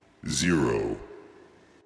描述：科幻相关的文字由男性说。用AT2020 + USB录制。有效果。